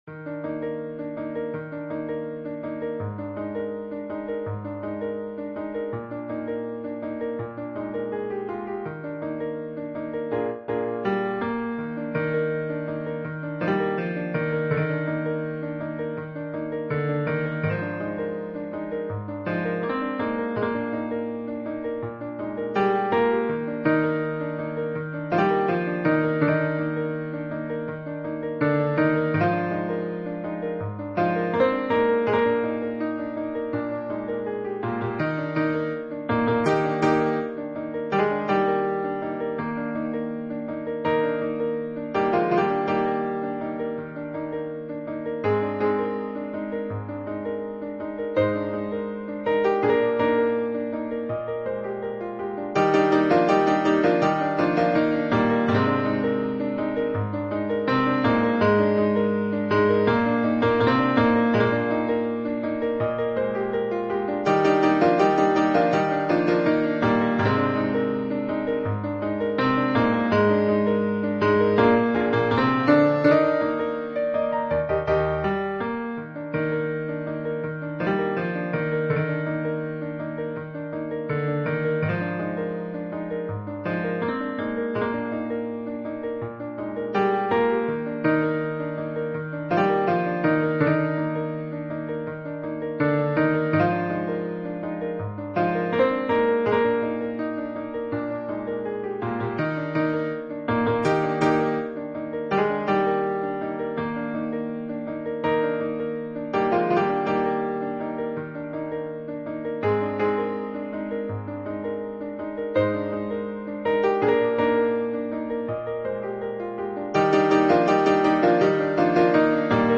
SATB mixed choir and piano
世俗音樂
簡易
合唱版本前奏從瀑布產生水波的意象出發，